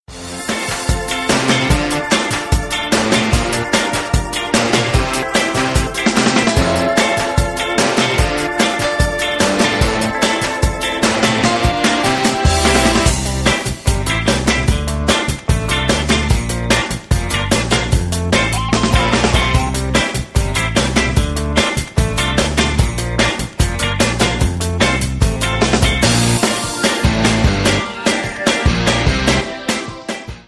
mitreißender und mitsingbarer
• Sachgebiet: Pop